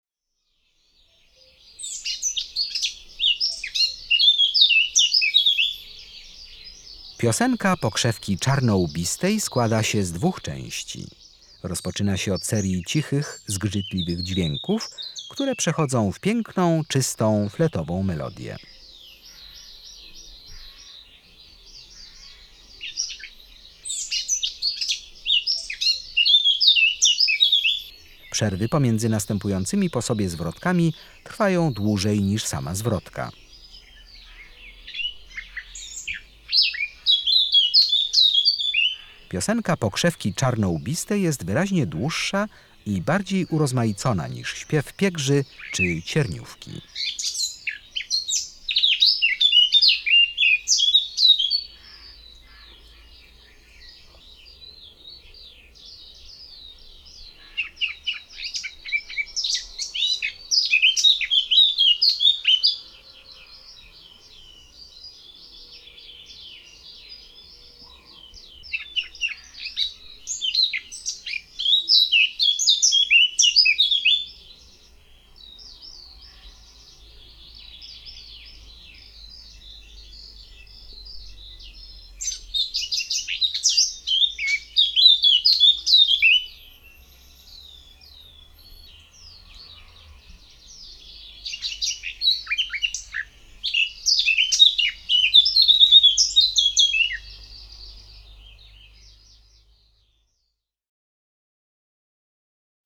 25-Pokrzewka czarnolbista.mp3